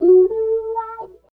70 GTR 6  -R.wav